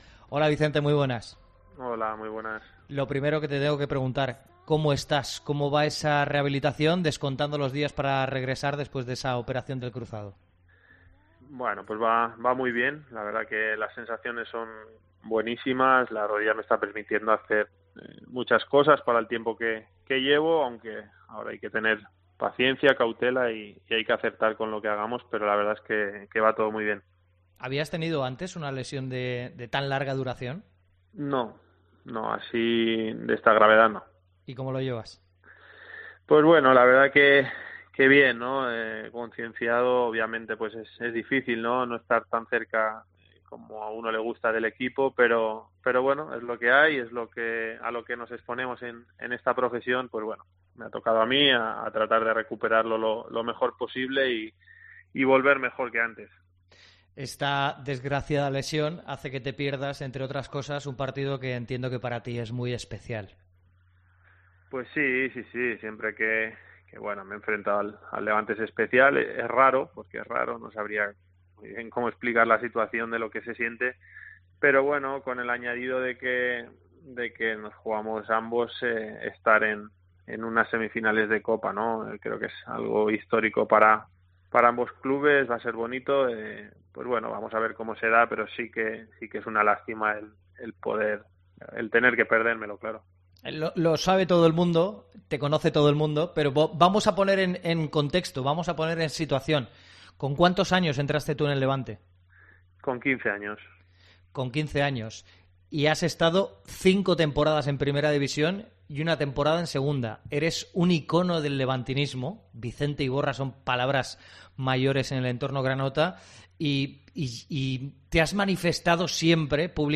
AUDIO. Entrevista a Iborra en COPE